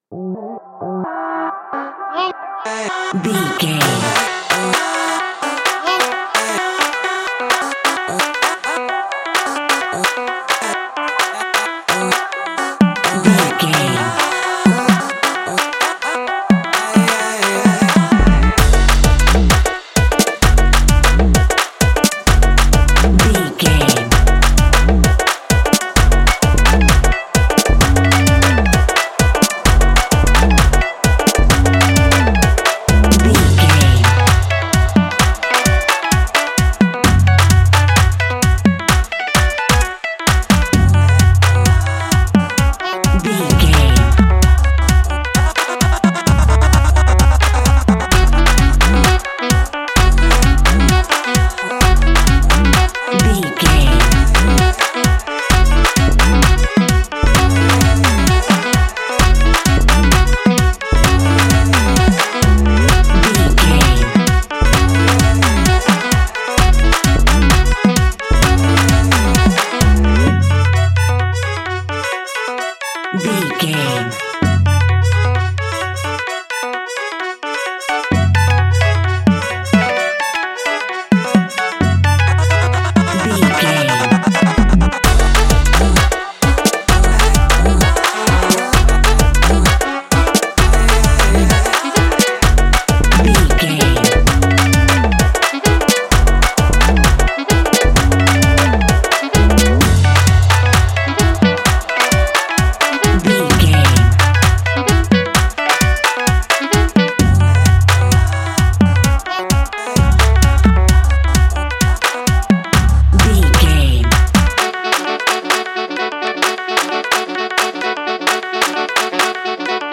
Fast paced
Ionian/Major
Afro-electro
percussion